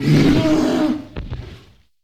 PixelPerfectionCE/assets/minecraft/sounds/mob/polarbear/death3.ogg at mc116